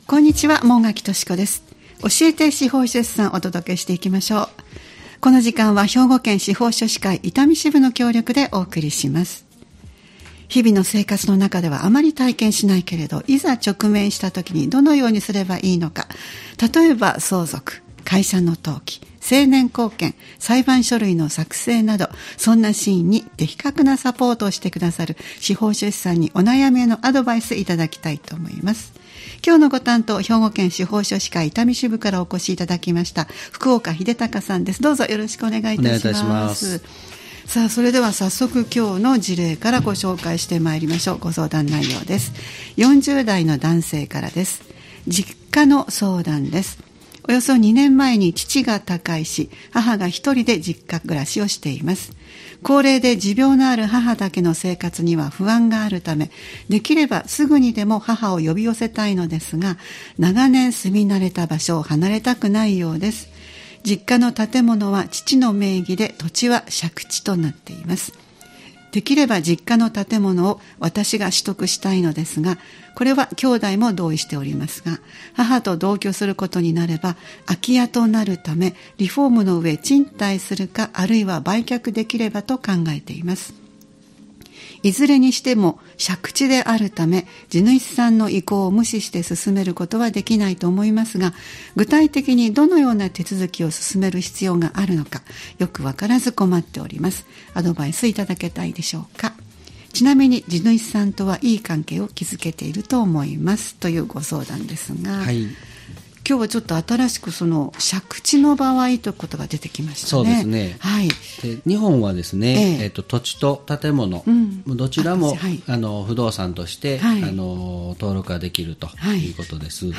毎回スタジオに司法書士の方をお迎えして、相続・登記・成年後見・裁判書類の作成などのアドバイスを頂いています。